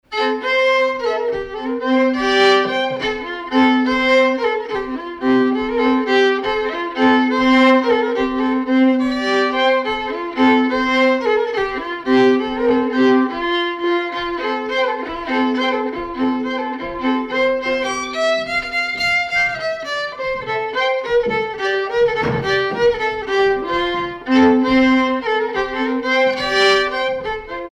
Danse
Villard-sur-Doron
circonstance : bal, dancerie
Pièce musicale inédite